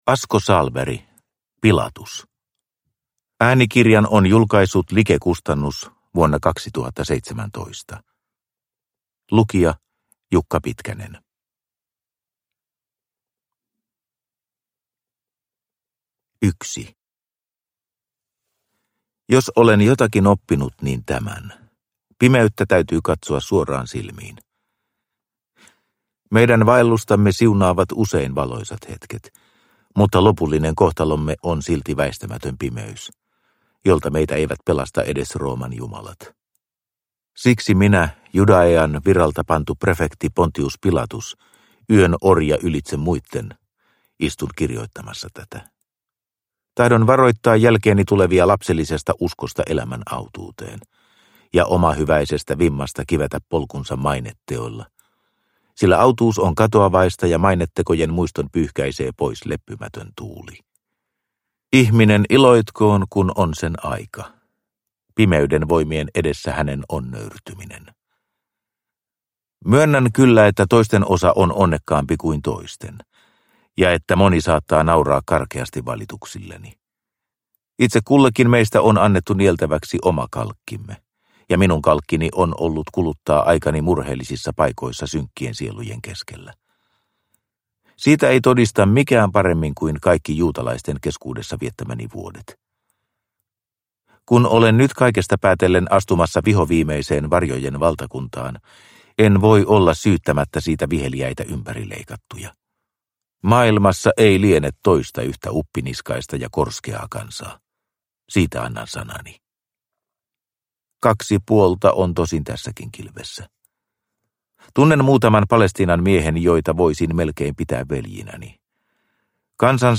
Pilatus – Ljudbok – Laddas ner